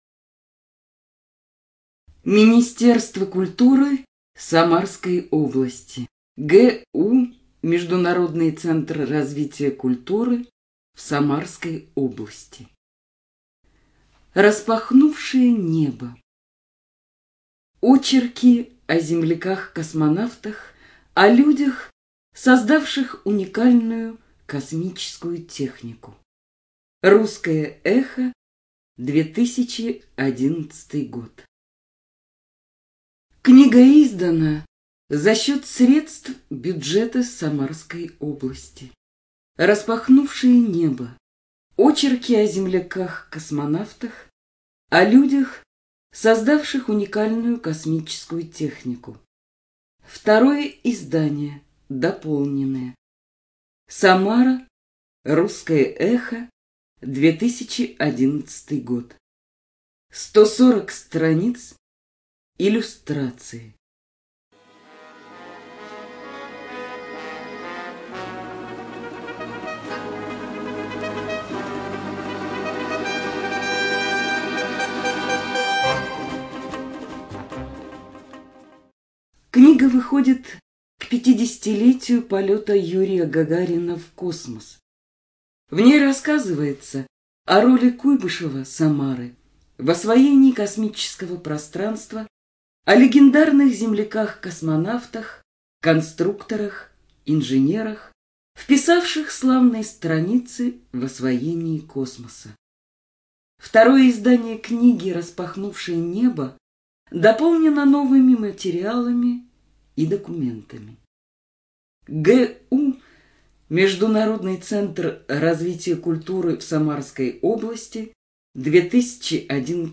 Студия звукозаписиСамарская областная библиотека для слепых